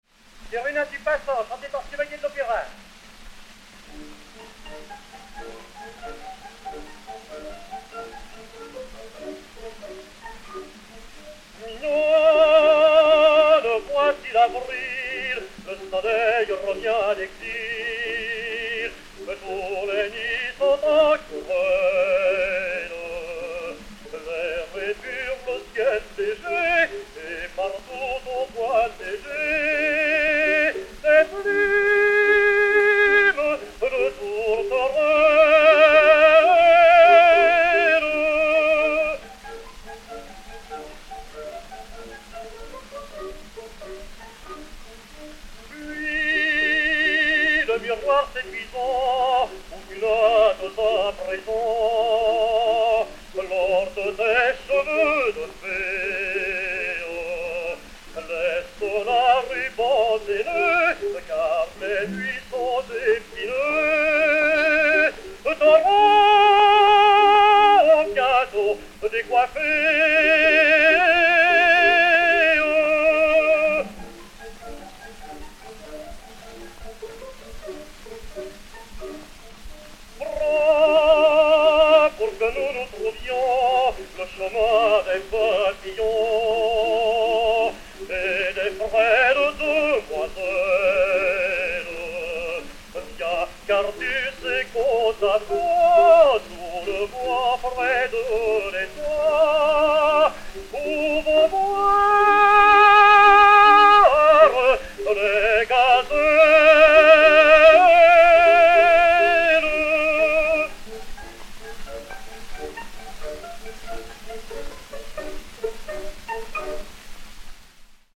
ténor, avec orchestre